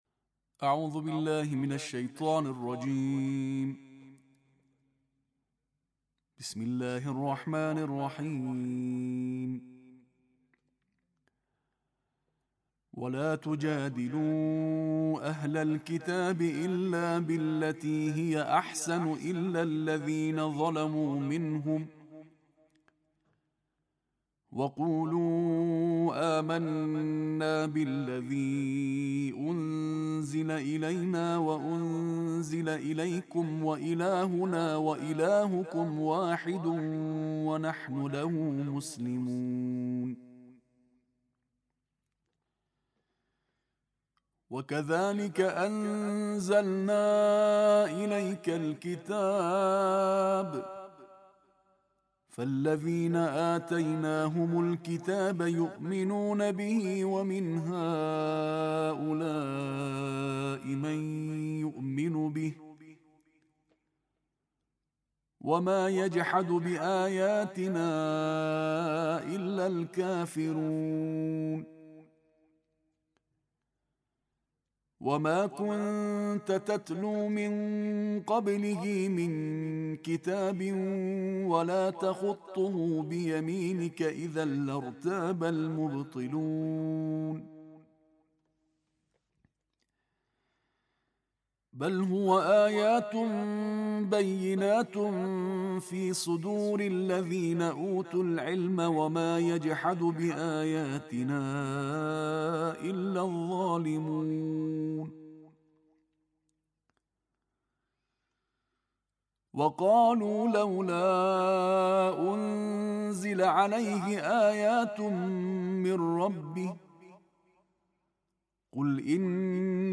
Récitation en tarteel de la 21e partie du Coran
La récitation est publiée pour la première fois.